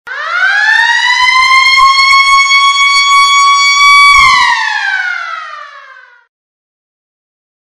sirene de escola
sirene-de-escola.mp3